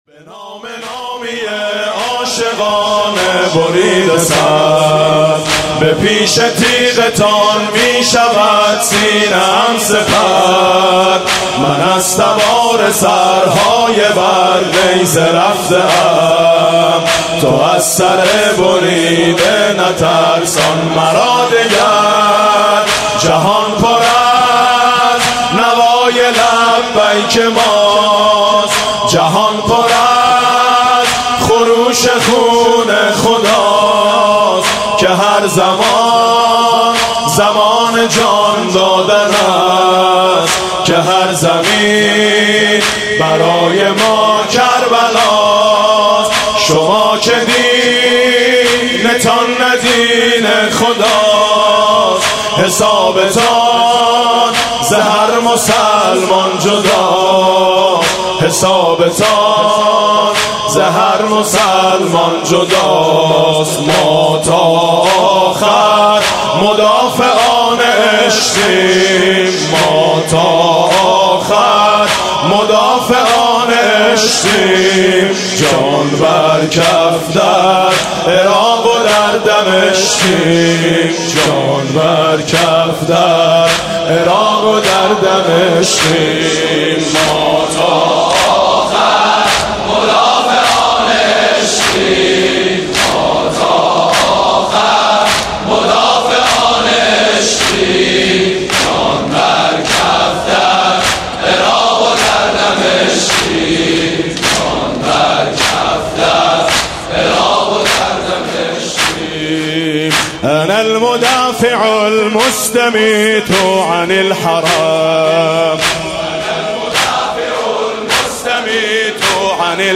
مداحی فارسی، عربی و ترکی
مرثیه فارسی، عربی و ترکی با بیانی حماسی